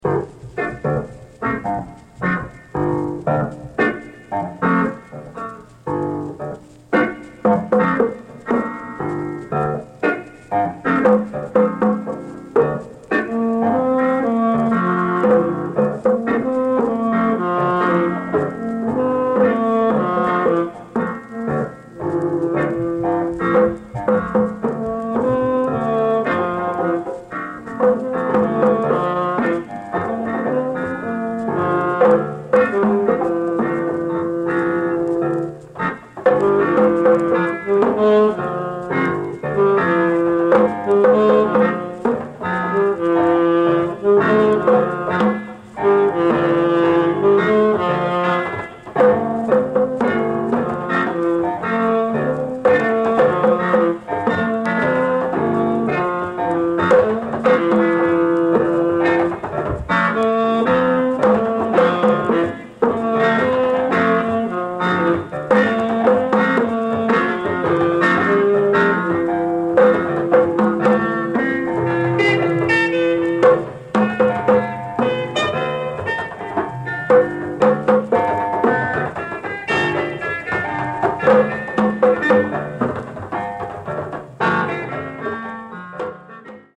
Jazz